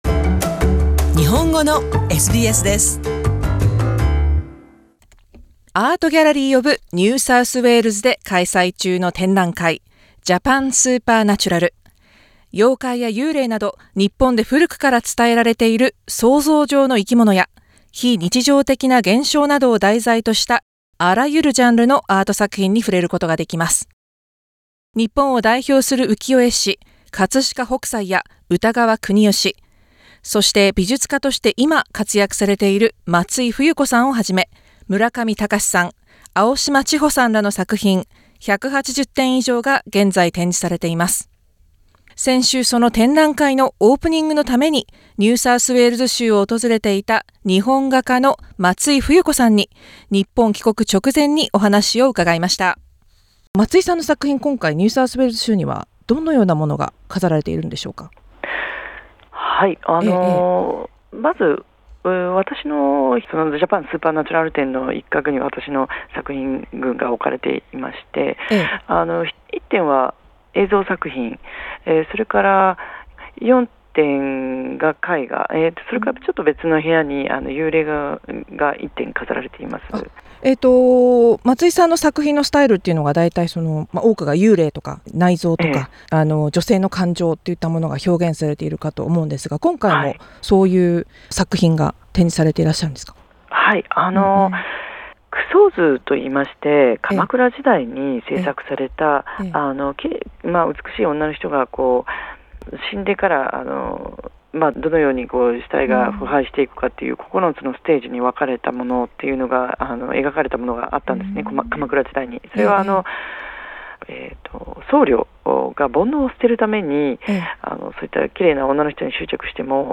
Art Gallery of New South Wales (ニューサウスウェールズ州立美術館)で開催中の展覧会 Japan Supernatural では、妖怪や幽霊など、日本で古くから伝えられている想像上の生き物や非日常的な現象などを題材としたあらゆるジャンルのアート作品、計180点以上が展示されています。 展覧会オープニングのためにシドニーを訪れていた 松井冬子 さんに、ご自身の作品についてなどお話を伺いました。